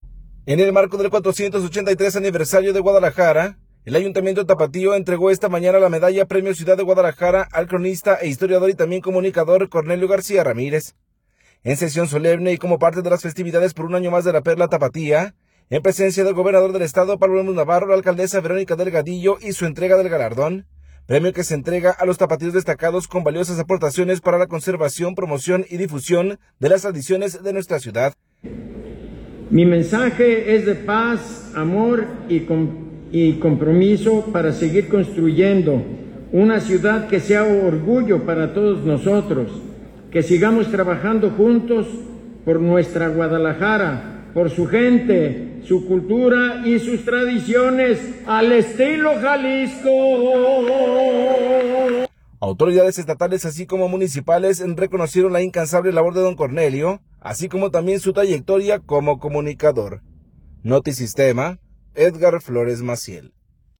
En sesión solemne y como parte de las festividades por un año más de La Perla Tapatía, en presencia del gobernador del Estado Pablo Lemus Navarro, la alcaldesa Verónica Delgadillo hizo entrega del galardón, premio que se entrega a los tapatíos destacados con valiosas aportaciones para la conservación, promoción y difusión de las tradiciones de nuestra ciudad.